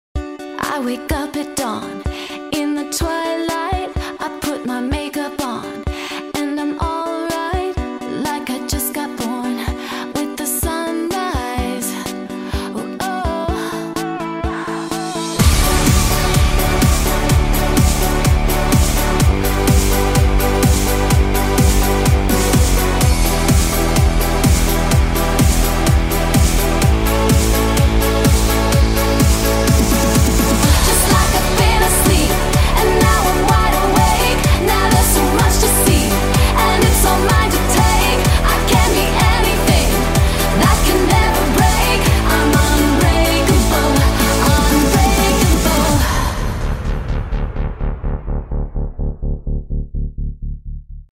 Start your day from a soothing sound and stylish design.